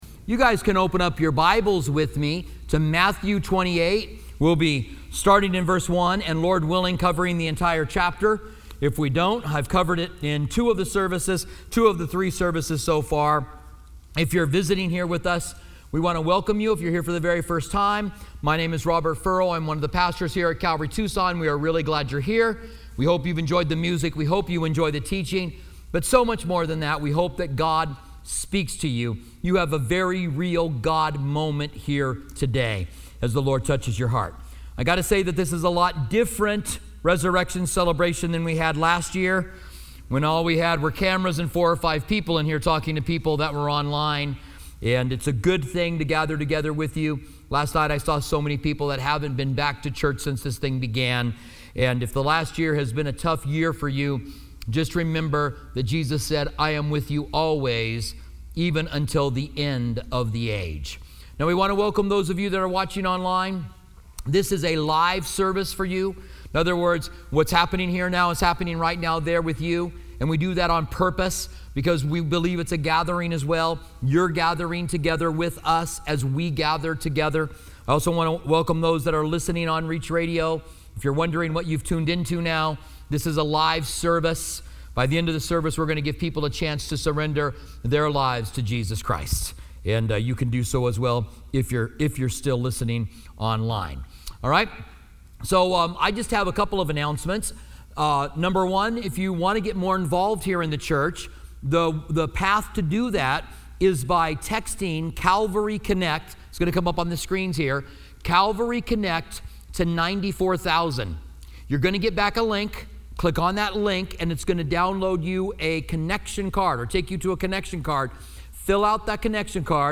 Easter message